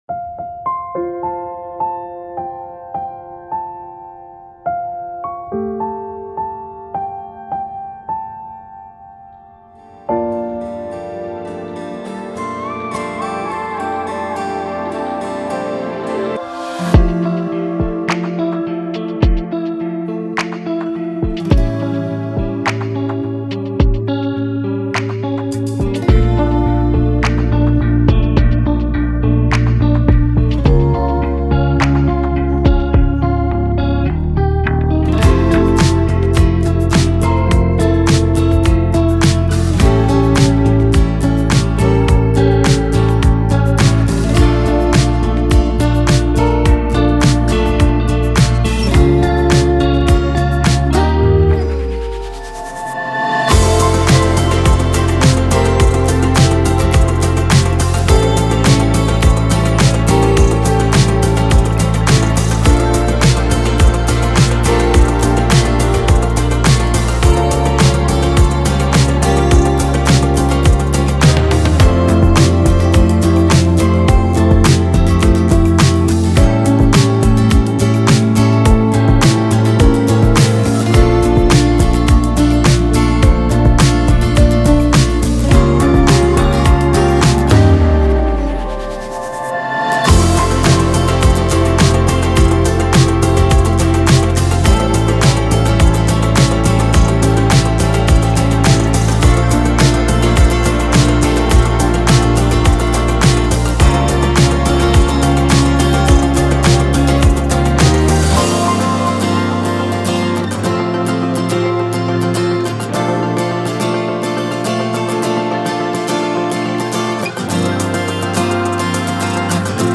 K-Pop Instrumentals , Official Instrumentals